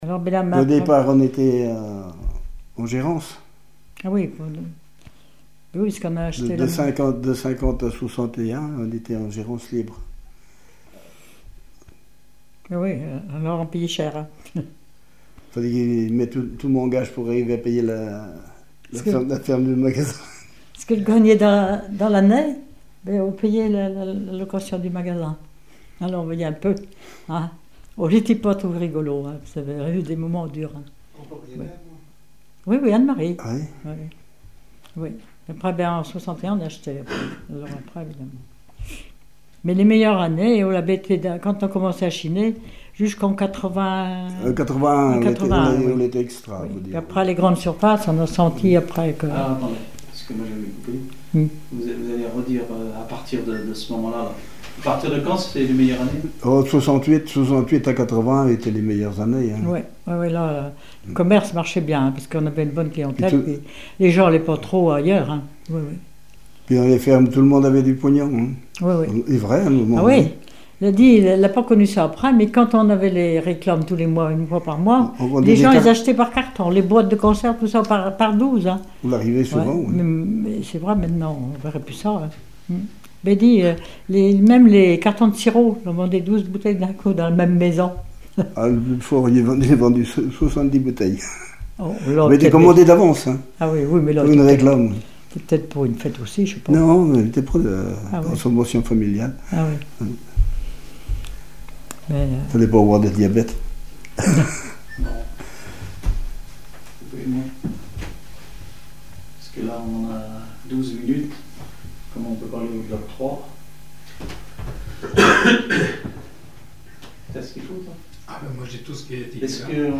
Témoignage sur un commerce
Catégorie Témoignage